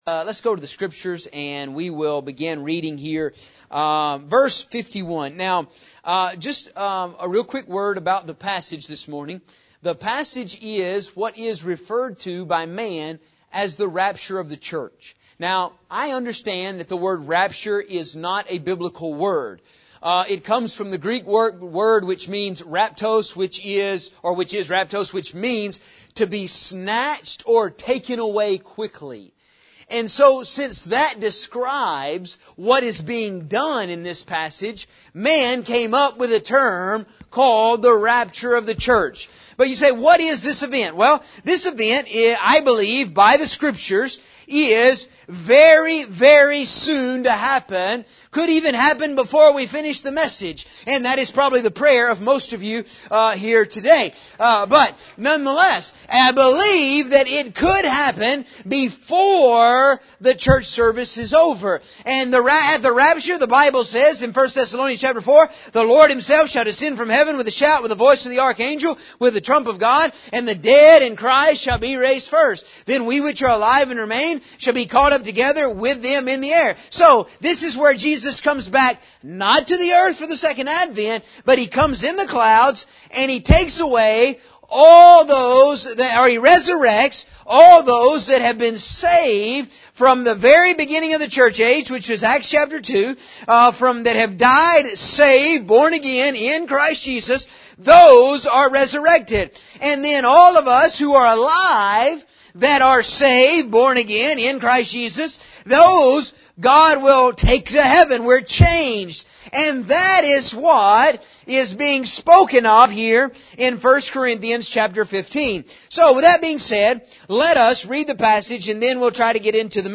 In the passage for this sermon we are dealing with the rapture of the church which is also detailed in 1 Thessalonians 4:13-18.